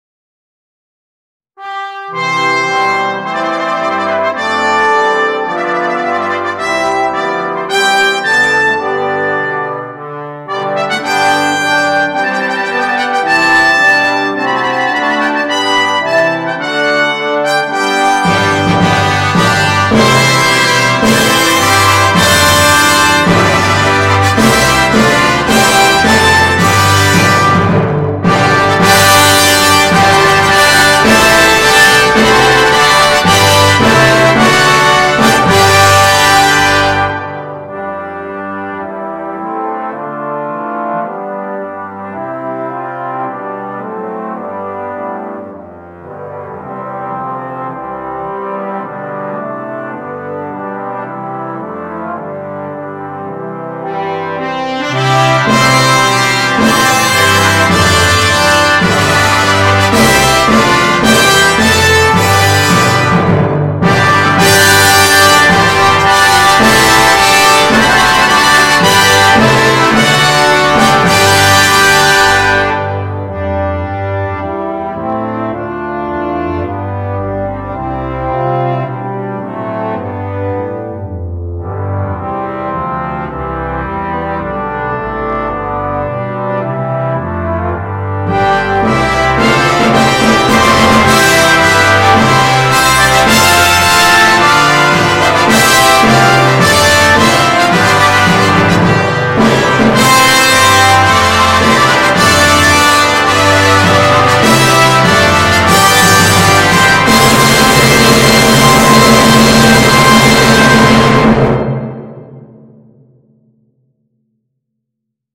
Voicing: 4 Trumpets, 2 Horns, 3 Trombones and Tuba